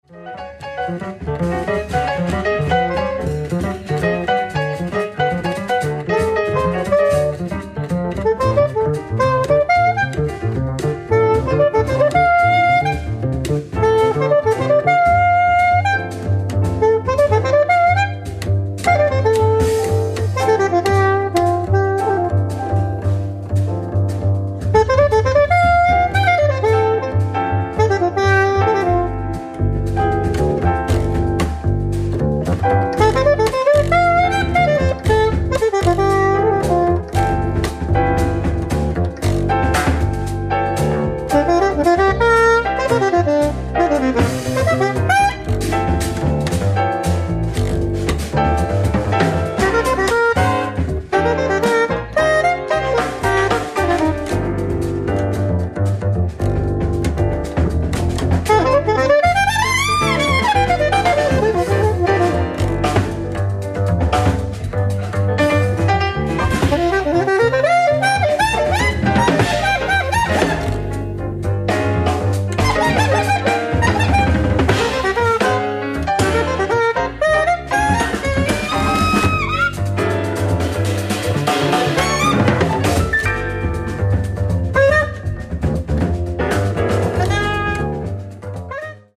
ディスク１＆２：ライブ・アット・トゥッレーノ劇場、ペルージア、イタリア 06/17/2002
※試聴用に実際より音質を落としています。